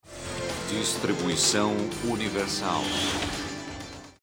Während man den Schriftzug für John Rhys-Davies einblendet, hört man noch, dass es sich um eine Serie der Firma Universal handelt.